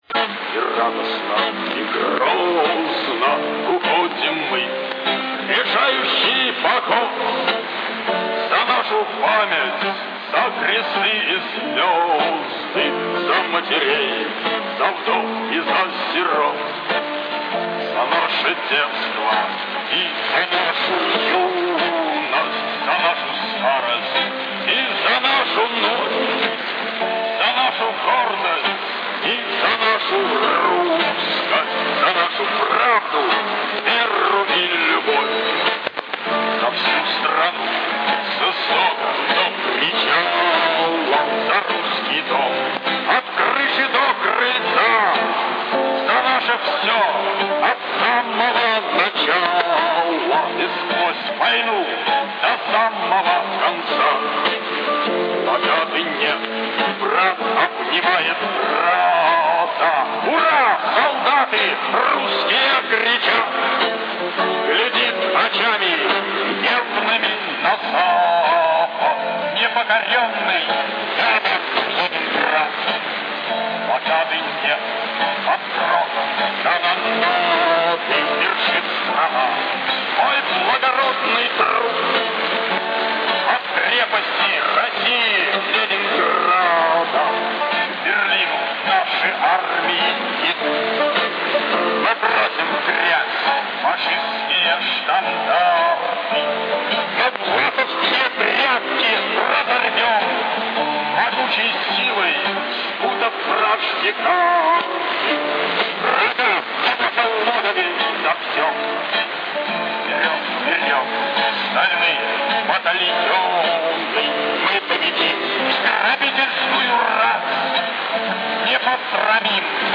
Вещание на 6990 кГц